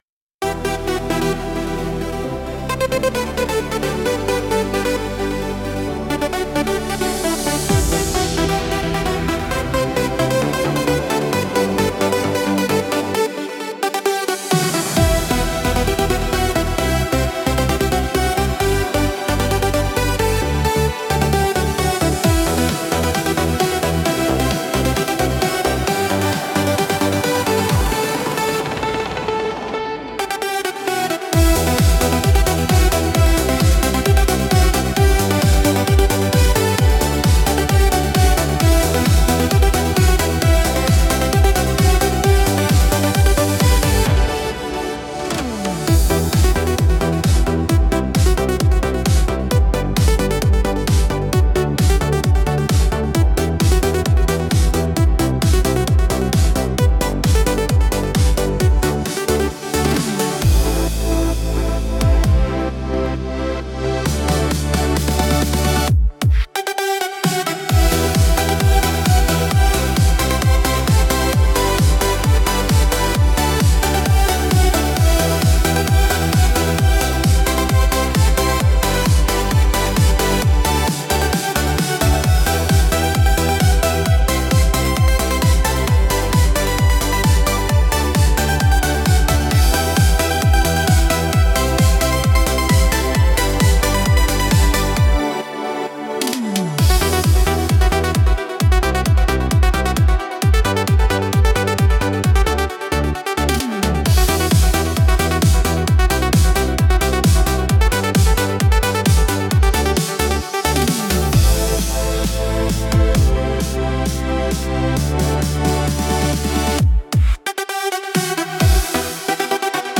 Instrumental - Paris Laserlight 2.59